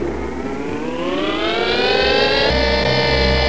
Cartoon Background Noise 5